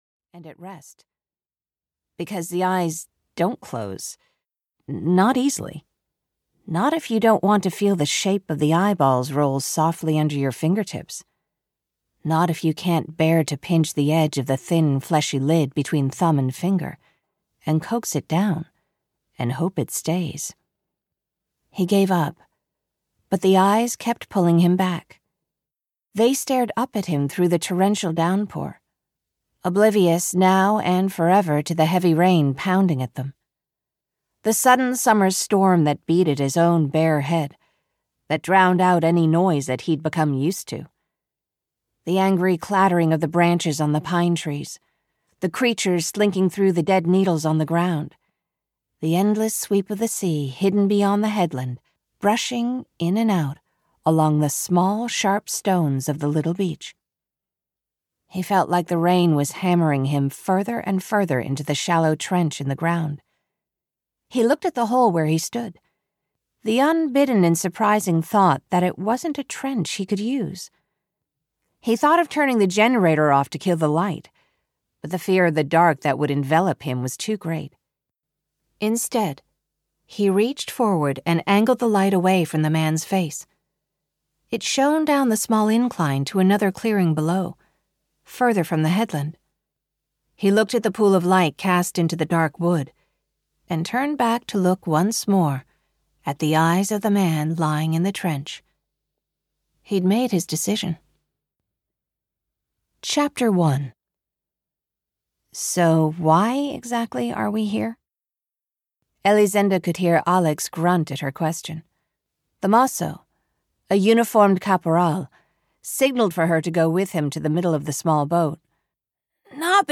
City of Buried Ghosts (EN) audiokniha
Ukázka z knihy